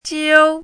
chinese-voice - 汉字语音库
jiu1.mp3